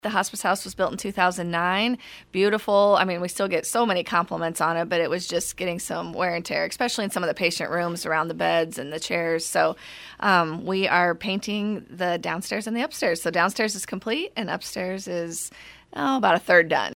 who laughs when she says one project always leads into another.